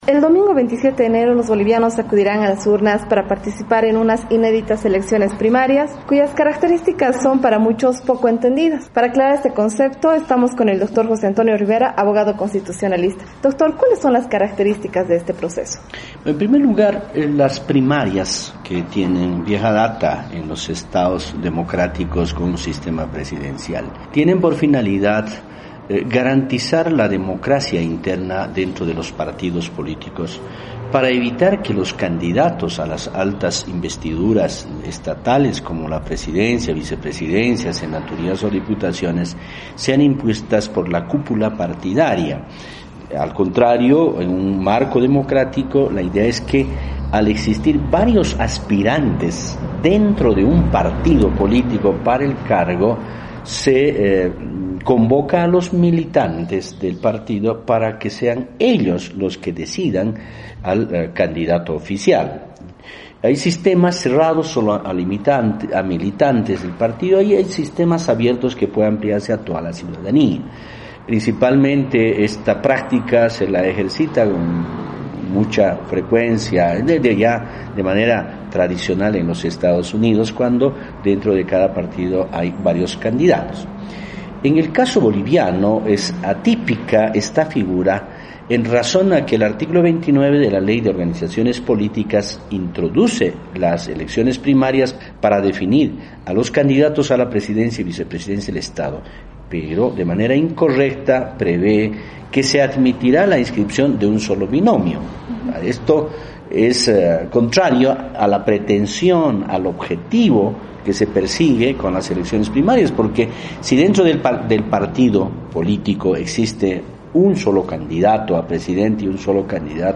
Entrevista: Bolivia acude a las urnas en cuestionadas elecciones primarias